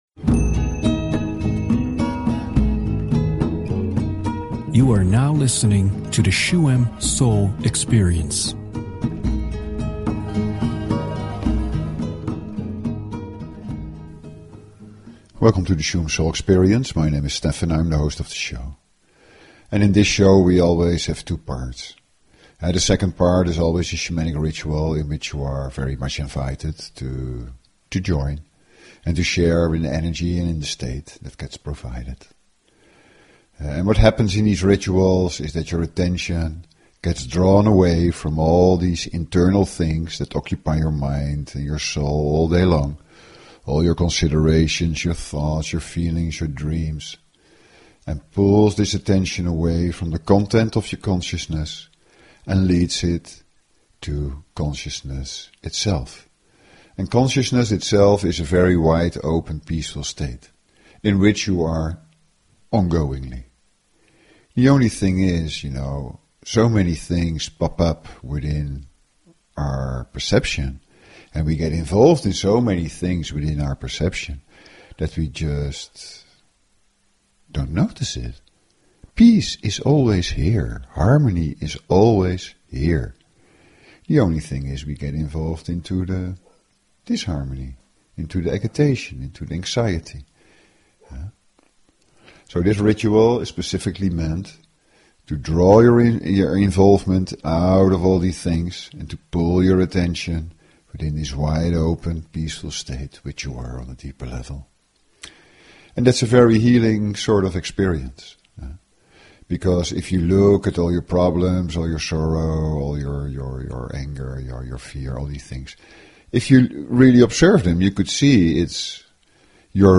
Talk Show Episode, Audio Podcast, Shuem_Soul_Experience and Courtesy of BBS Radio on , show guests , about , categorized as
The second part of the show is a shamanic healingmeditation in which negativity and heavy feelings can transform. When you exclusively listen to it and let the sounds of drum, rattle and chant flow through you feel more peaceful at the end which is the basics to find this contentment.